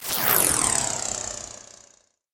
Звуки вращения
На этой странице собраны разнообразные звуки вращения: от легкого шелеста крутящихся лопастей до мощного гула промышленных механизмов.
Звук верчения